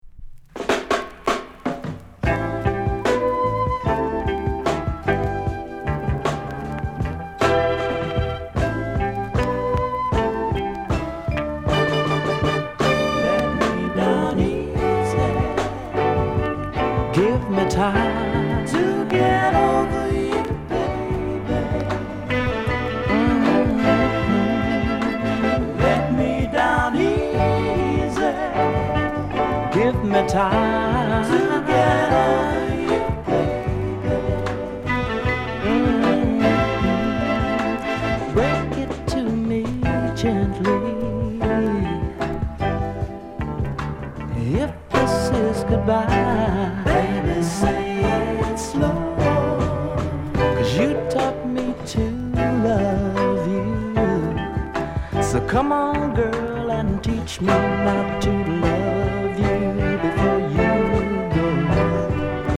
RARE SOUL